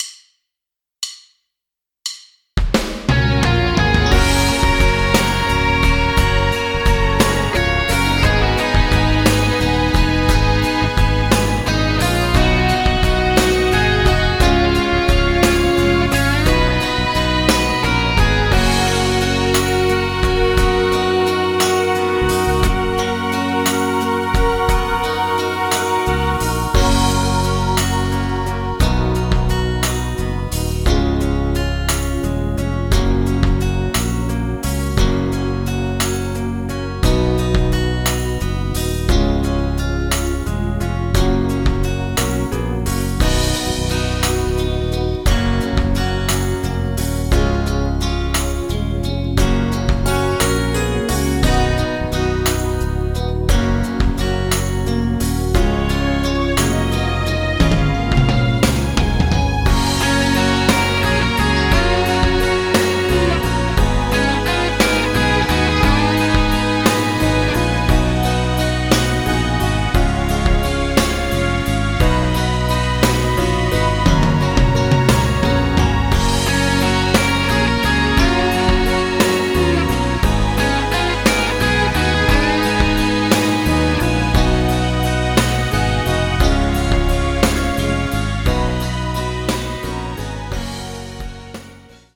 Playback, Karaoke, Instrumental, Midi